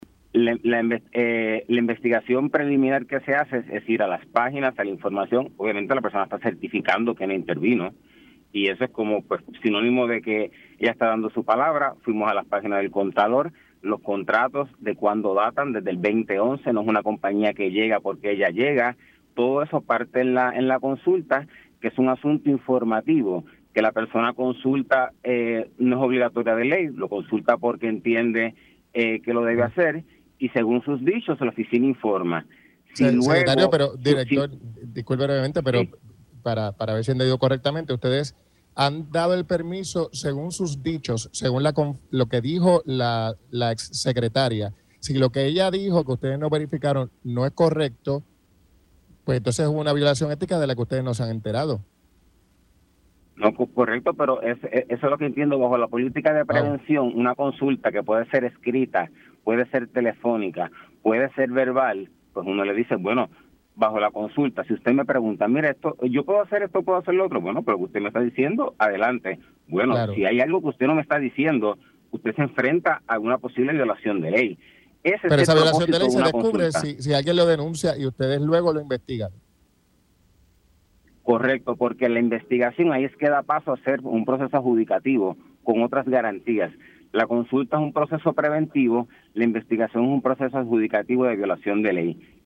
El director ejecutivo de la Oficina de Ética Gubernamental (OEG), Luis Pérez Vargas afirmó en Pega’os en la Mañana que la investigación preliminar de la exsecretaria del Departamento de Transportación y Obras Públicas (DTOP), Eileen Vélez confiaron en su palabra que se inhibió en la otorgación de contratos de la agencia a Kimley-Horn, firma de ingeniería donde ahora labora como Directora Estratégica de Infraestructura.